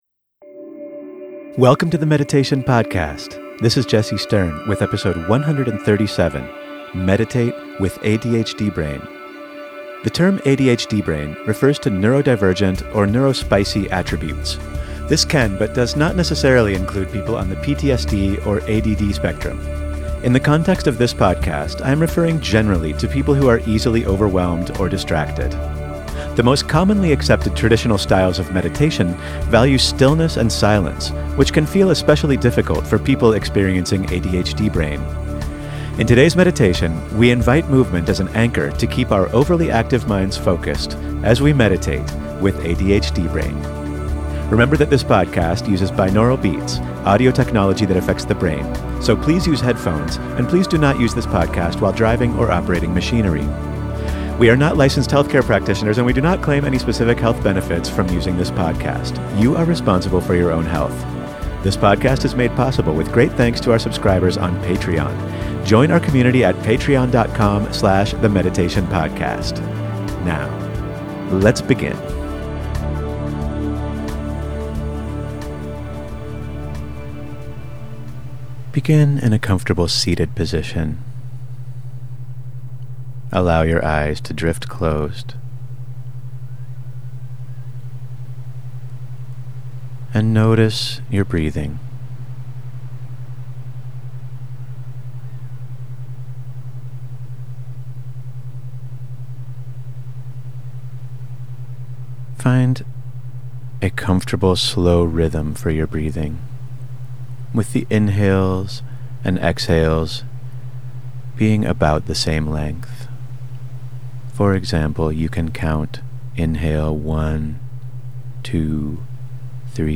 tmp137-meditate-with-adhd-brain.mp3